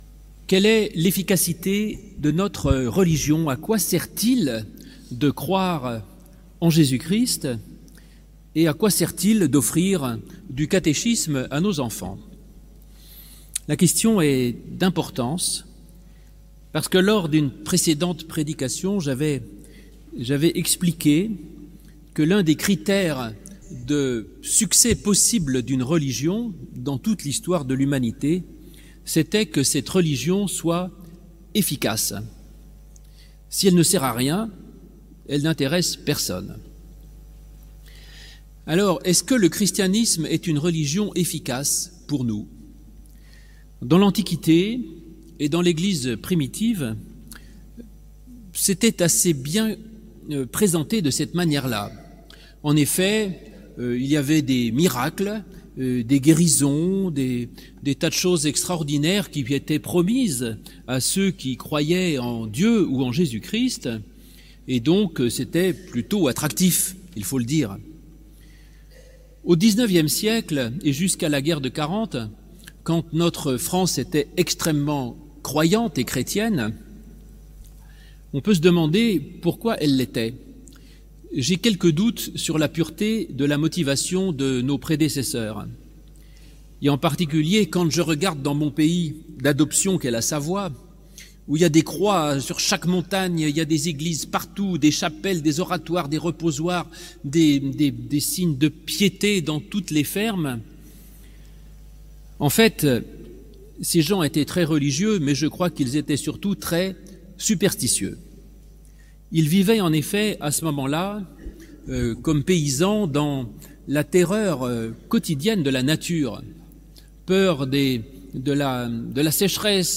Prédication prononcée le 16 juin 2024, au temple de l'Étoile à Paris